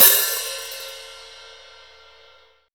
Percs
PERC.66.NEPT.wav